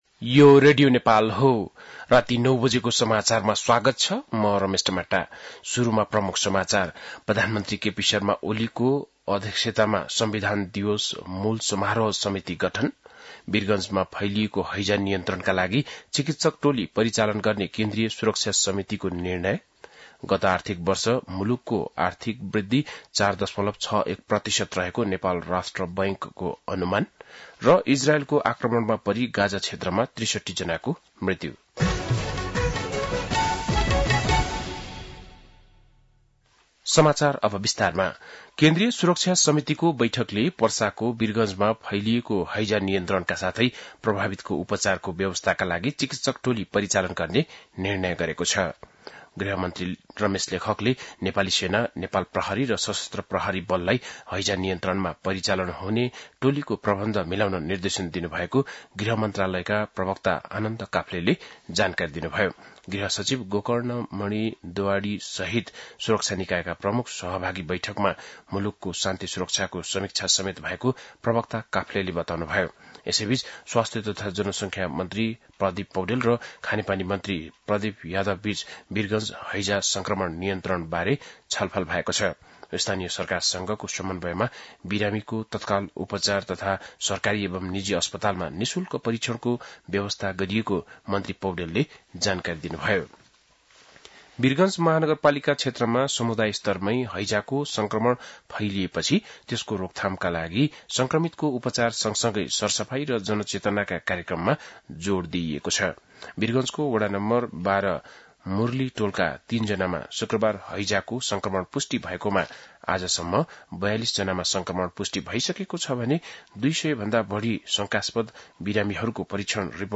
बेलुकी ९ बजेको नेपाली समाचार : ९ भदौ , २०८२
9-pm-nepali-news-5-8.mp3